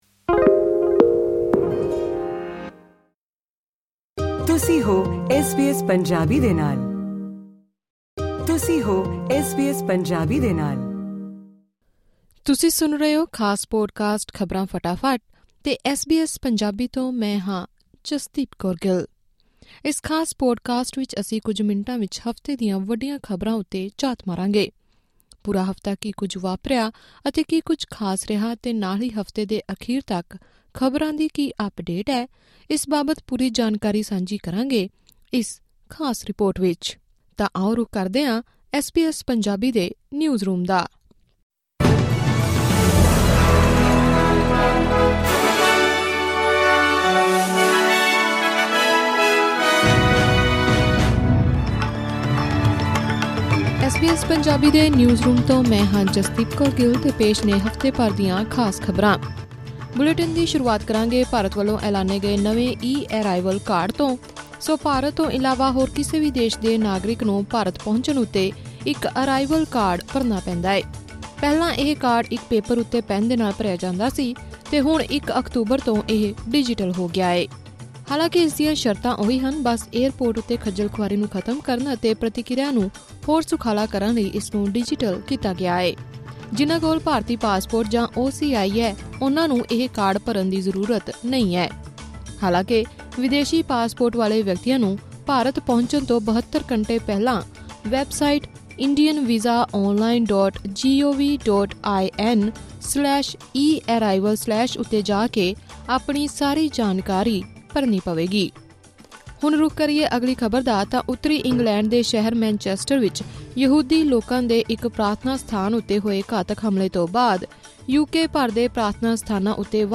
Weekly News Wrap.